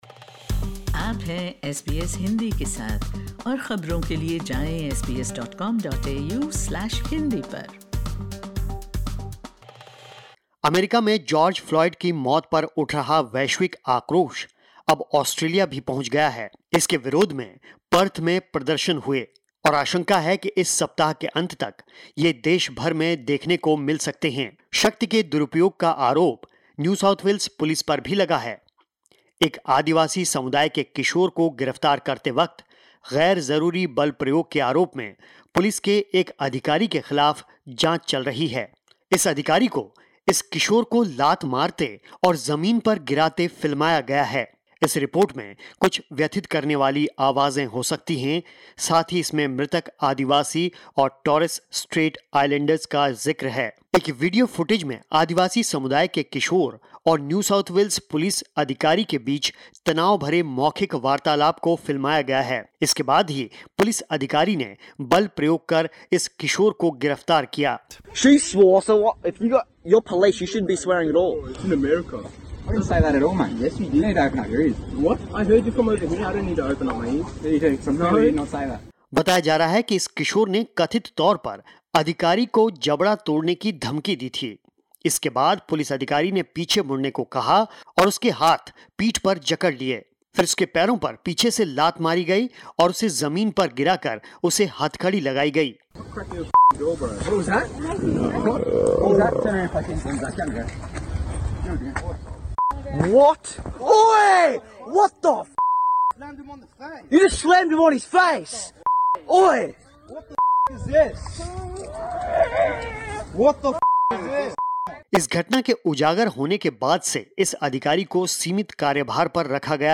(इस ऑडियो रिपोर्ट में व्यथित करने वाली आवाज़ें हो सकती हैं.